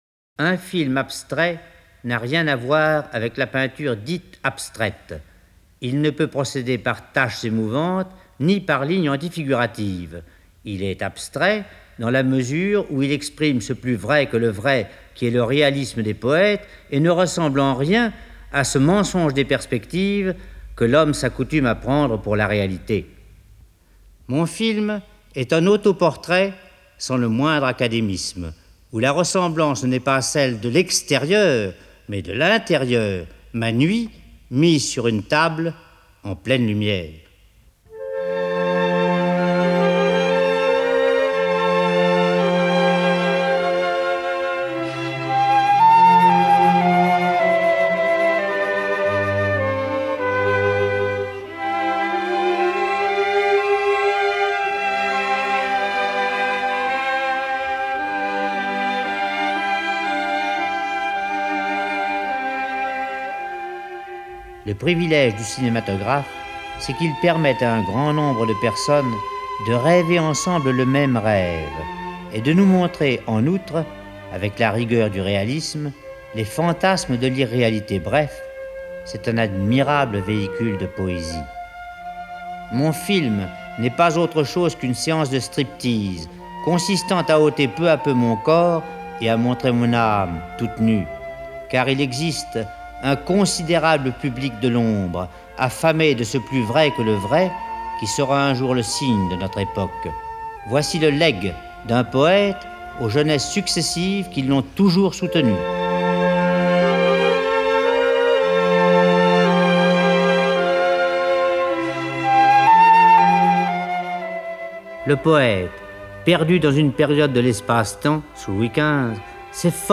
* Jean Cocteau dit :